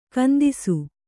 ♪ kandisu